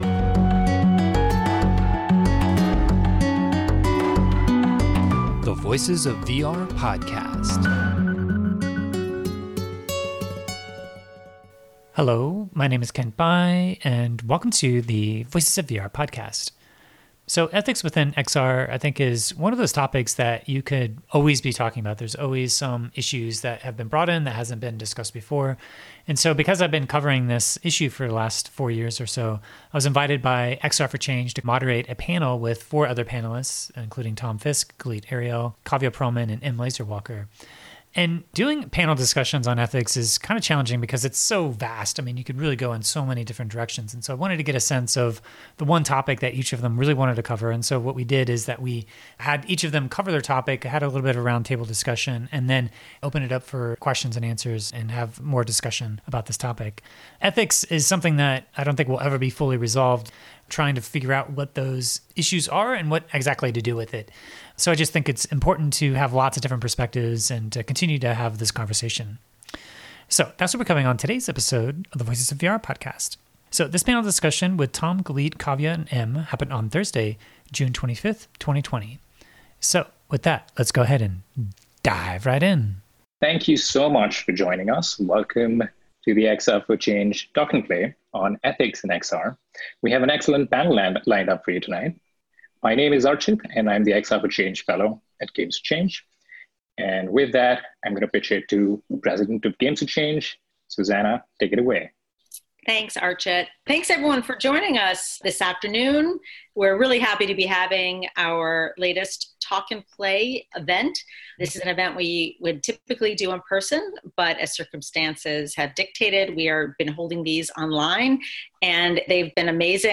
#930: XR for Change Panel on Ethics in XR – Voices of VR Podcast